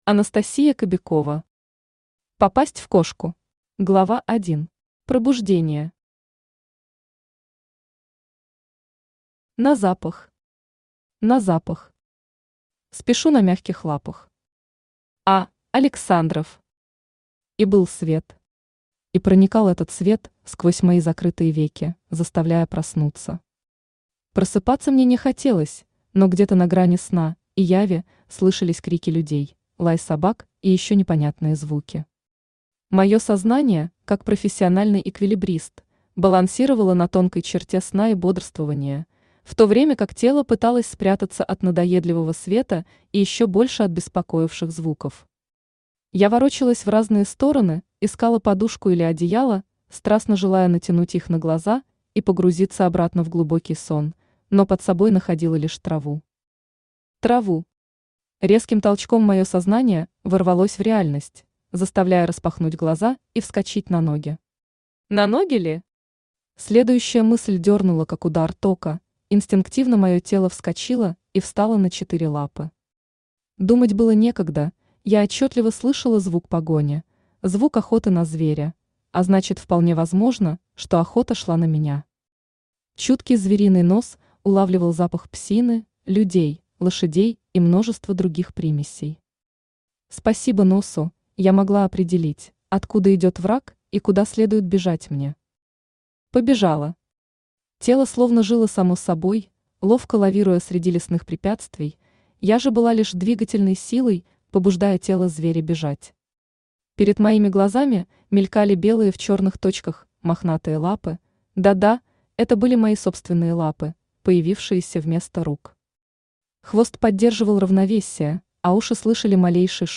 Аудиокнига Попасть в кошку | Библиотека аудиокниг
Aудиокнига Попасть в кошку Автор Анастасия Кобякова Читает аудиокнигу Авточтец ЛитРес.